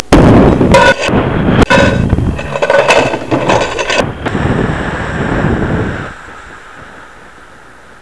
1 channel
snd_15975_crash.wav